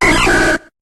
Cri de Qwilfish dans Pokémon HOME.